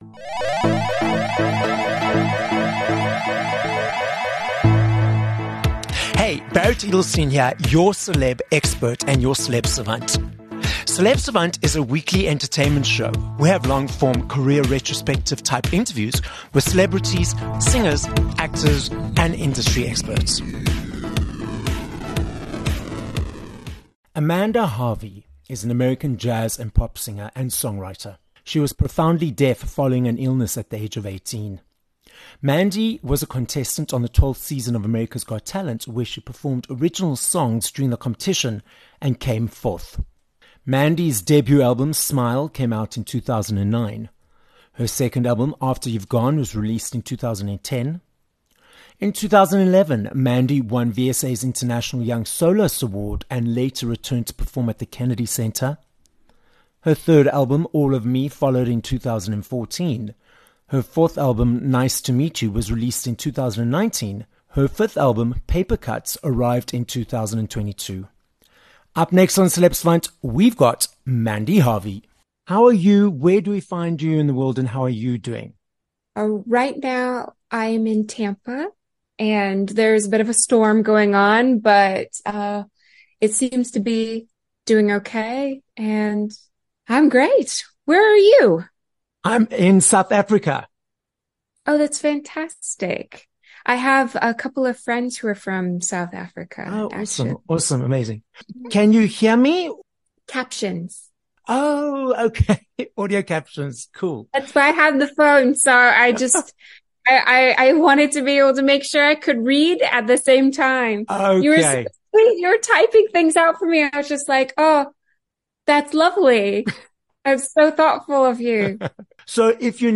16 Nov Interview with Mandy Harvey
American deaf singer and songwriter, Mandy Harvey is the guest on this episode of Celeb Savant. Mandy explains that her original focus was music education rather than performance, but she completely lost her hearing during her studies, which redirected her focus to become a successful performer.